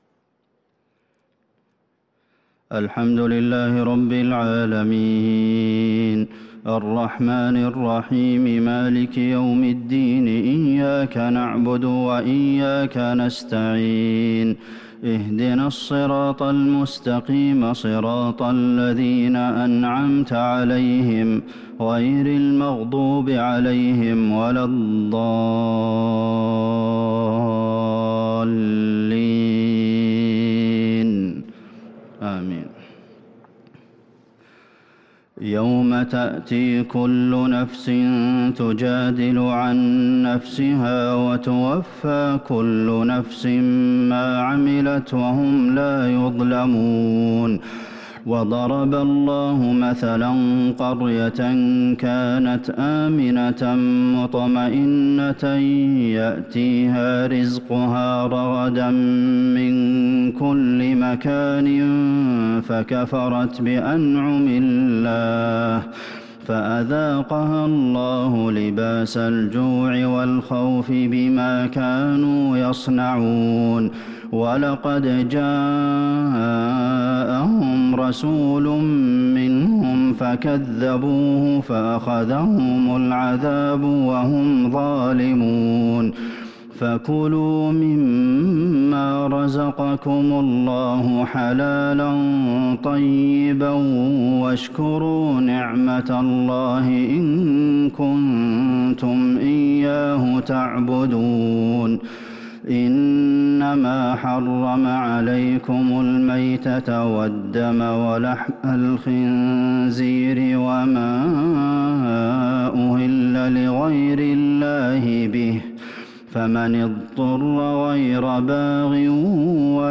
صلاة العشاء للقارئ عبدالمحسن القاسم 8 صفر 1443 هـ